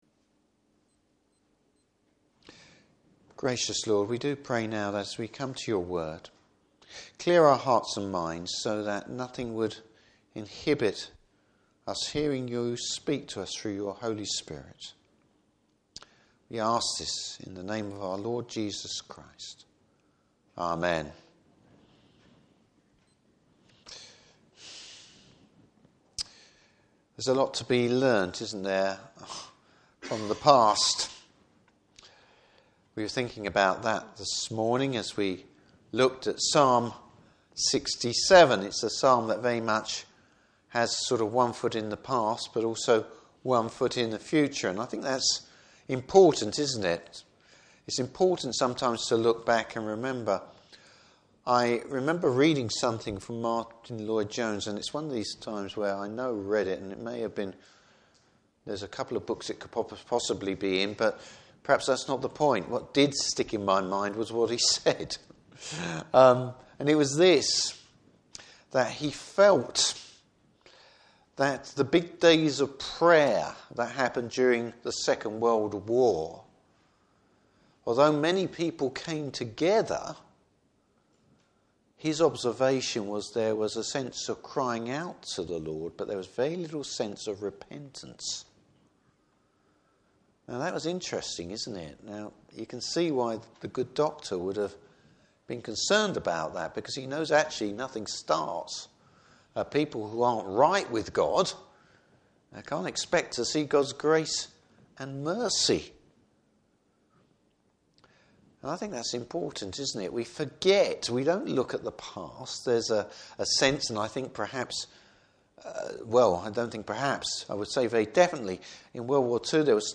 Service Type: Evening Service Bible Text: Deuteronomy 27:1-15.